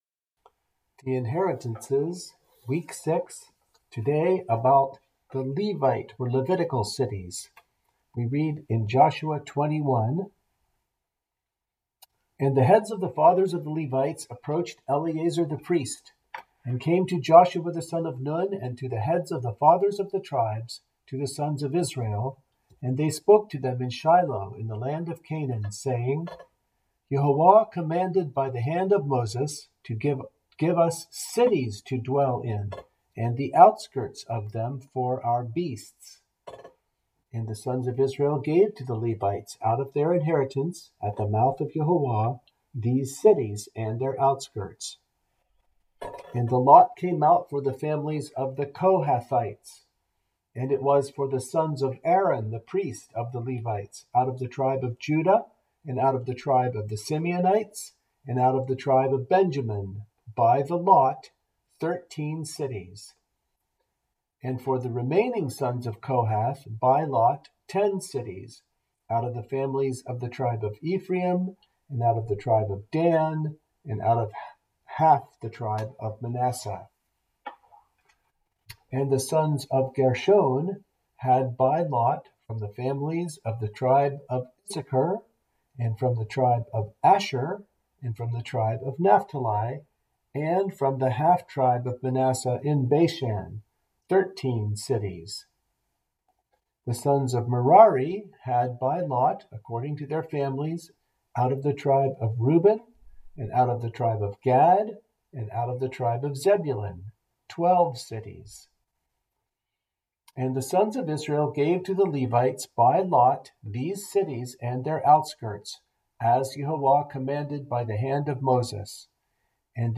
Kempton New Church - Study Group Readings